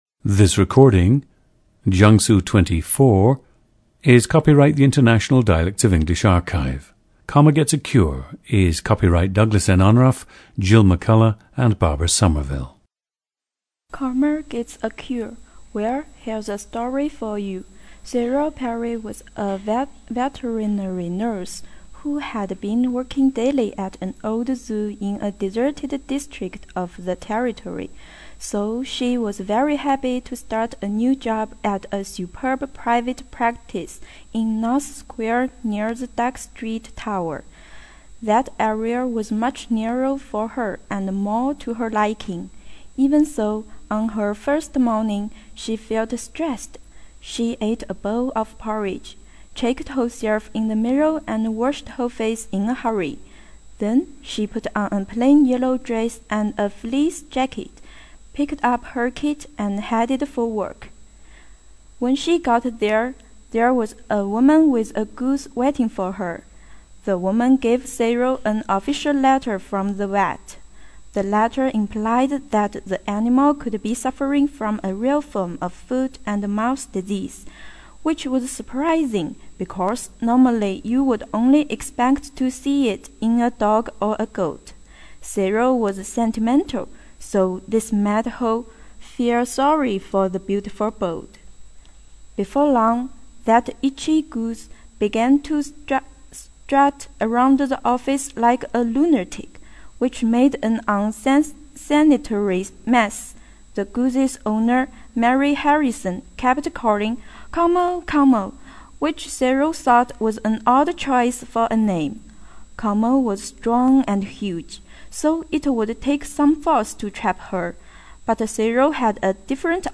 GENDER: female
She did have one native English-speaking teacher at school, and there has clearly been some accent reduction.
This is a very good example of the soft, melodious even, Chinese voice that one sometimes wishes was far more common than it is! His pronunciation is mainly noticeable for the /s/, /T/, /D/ minimal-pair substitutions, although there are traces of the common /l/, /n/, and /r/ substitutions also. Notice also how he reduces/suppresses the plosive quality of his /t/ and /d/ consonants.
• Recordings of accent/dialect speakers from the region you select.